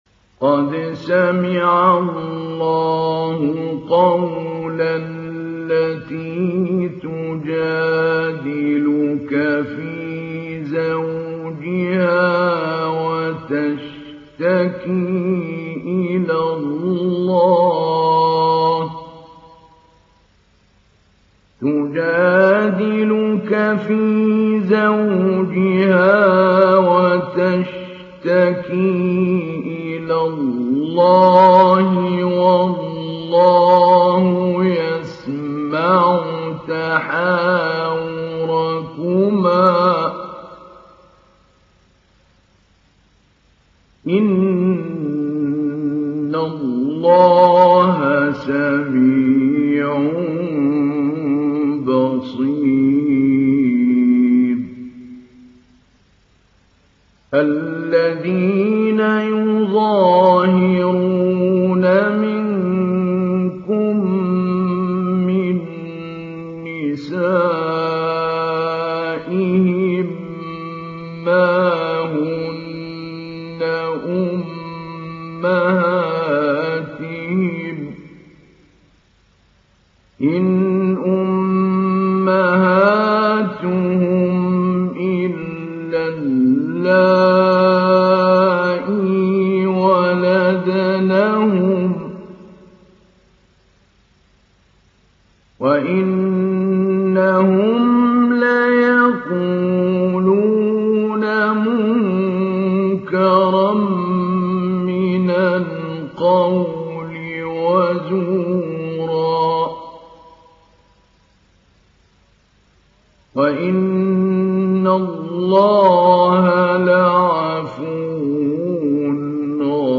Surah Al Mujadilah mp3 Download Mahmoud Ali Albanna Mujawwad (Riwayat Hafs)
Download Surah Al Mujadilah Mahmoud Ali Albanna Mujawwad